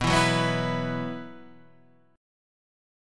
B Suspended 4th